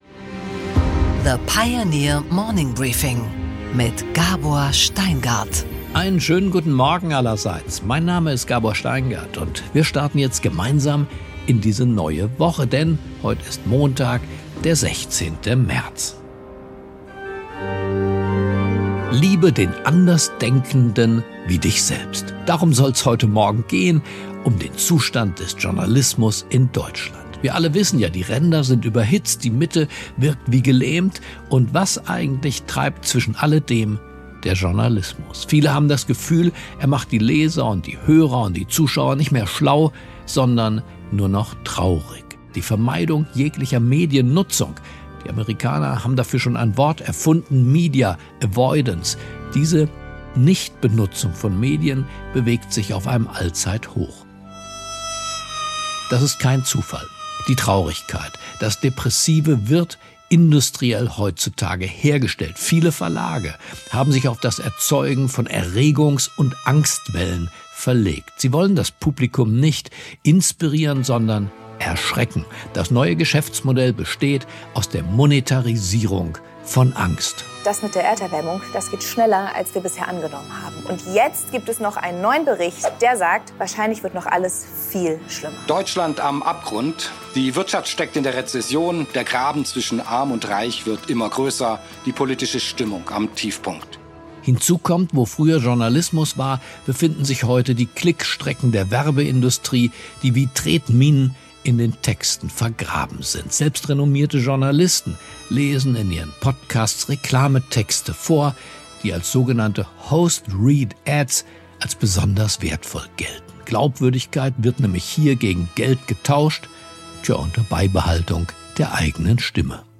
Gabor Steingart präsentiert das Morning Briefing.
Das ganze Gespräch mit Gabor Steingart hören Sie hier.